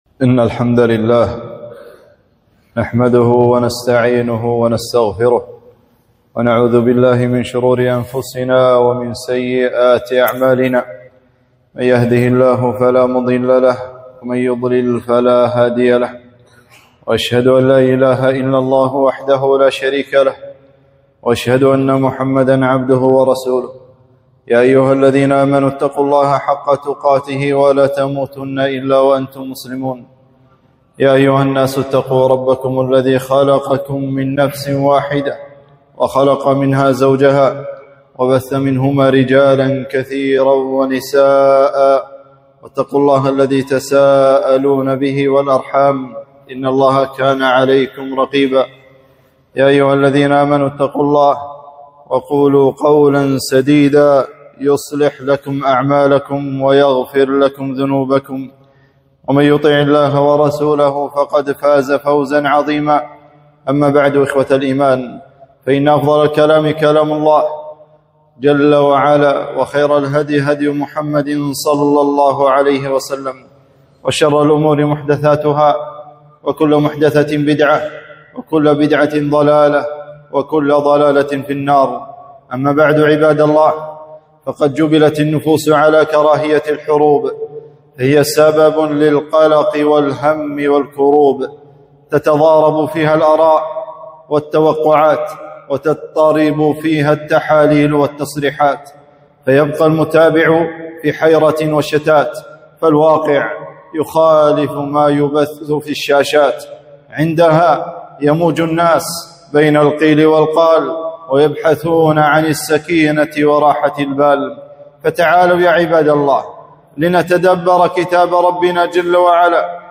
خطبة - آثار الحرب وفضل الرباط - دروس الكويت